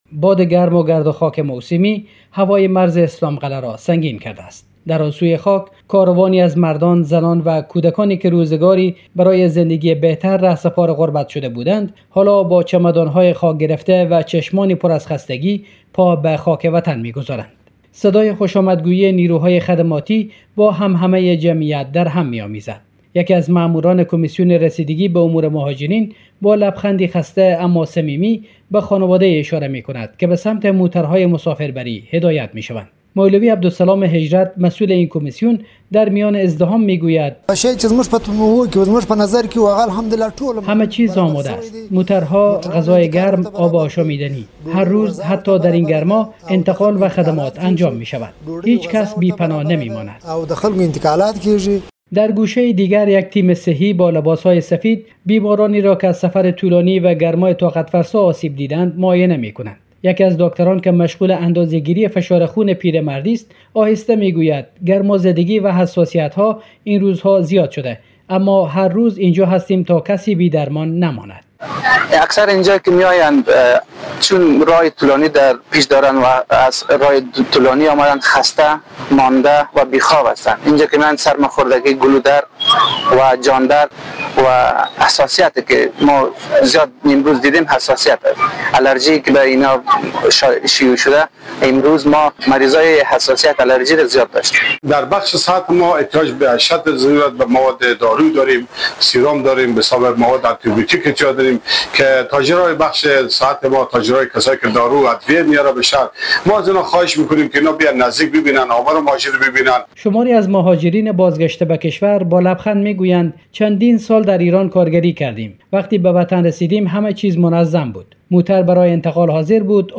صدای خوش‌آمدگویی نیروهای خدماتی با همهمه جمعیت درهم می‌آمیزد.
از بلندگو صدایی به گوش می‌رسد که گروه دیگری را به سمت اتوبوس‌ها راهنمایی می‌کند و گاهی نیز اسامی را اعلام میکند که از خانواده‌هایشان جدا افتادند.